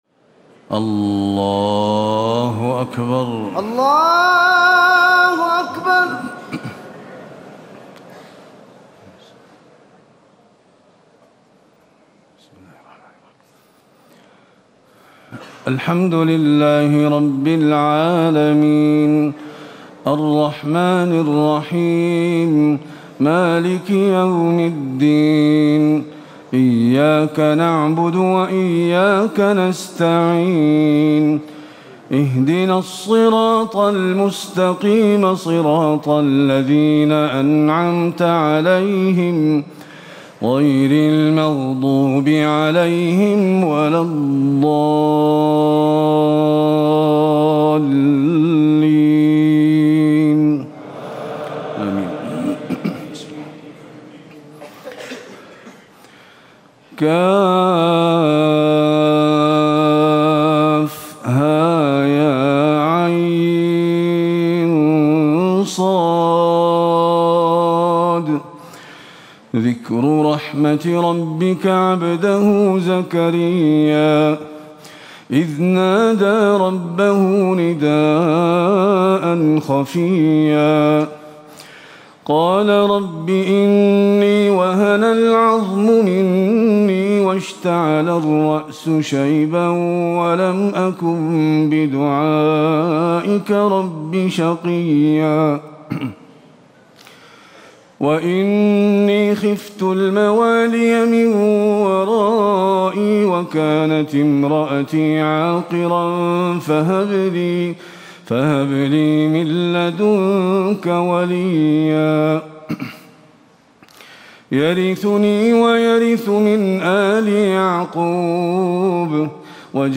تراويح ليلة 30 رمضان 1437هـ سورة مريم كاملة Taraweeh 30 st night Ramadan 1437H from Surah Maryam > تراويح الحرم النبوي عام 1437 🕌 > التراويح - تلاوات الحرمين